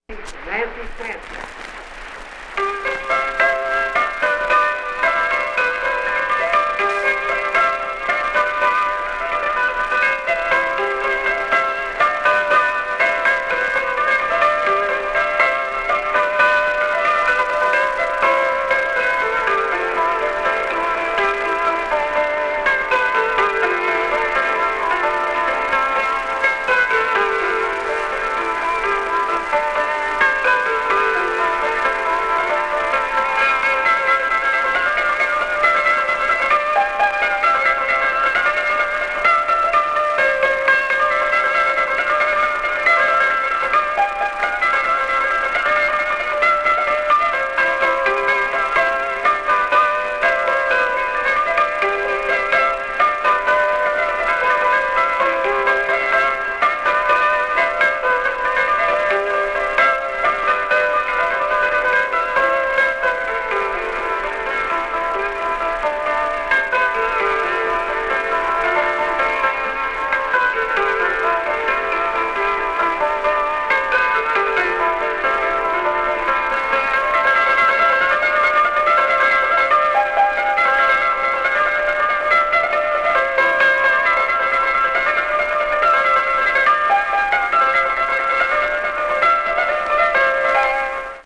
Cimbalom
打擊樂器 (Percussion Instruments)
The hammered dulcimer developed from the cimbalom, an instrument from Hungary with 48 strings that is played with small hammers.
Northern California Folk Music from the Thirties